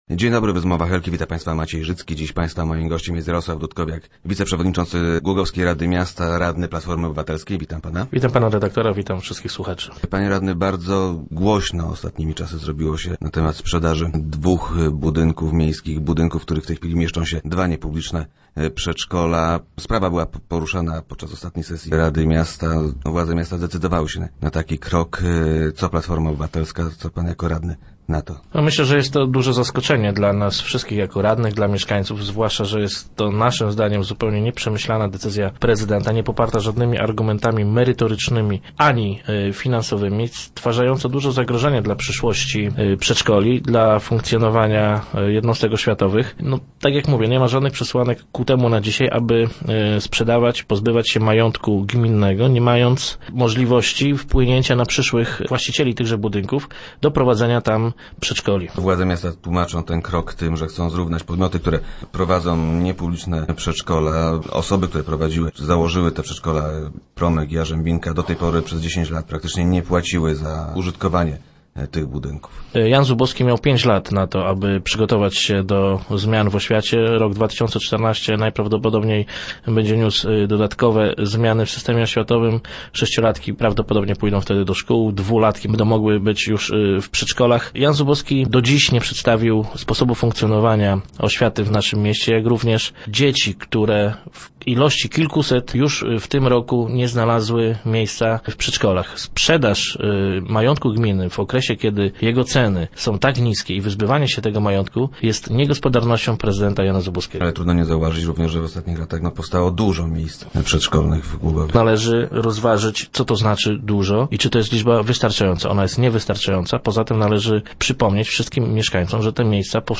Rodzice przedszkolaków grożą referendum w sprawie odwołania prezydenta. - Wiemy jak rozwiązać ten problem – twierdzi radny Jarosław Dudkowiak, który był gościem Rozmów Elki.